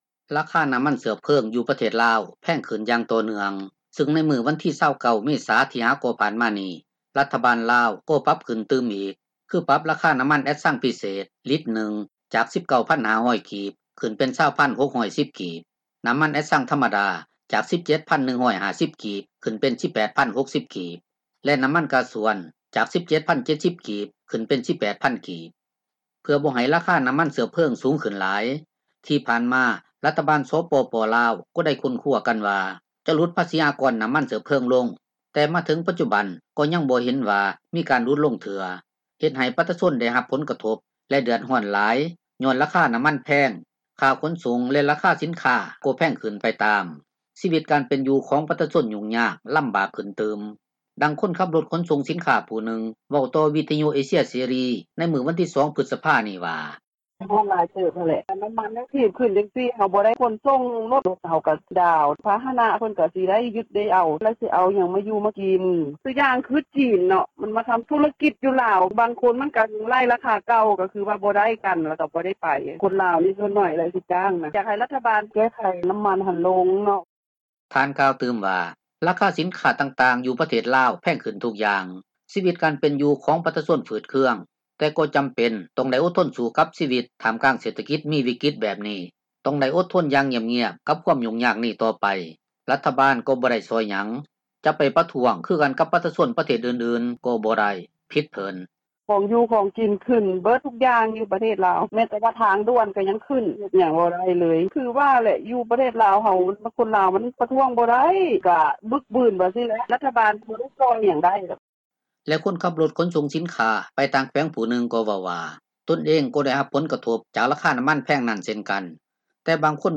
ດັ່ງຄົນຂັບຣົຖຂົນສົ່ງສິນຄ້າ ຜູ້ນຶ່ງເວົ້າຕໍ່ວິທຍຸເອເຊັຽເສຣີ ໃນມື້ວັນທີ 2 ພຶສພານີ້ວ່າ: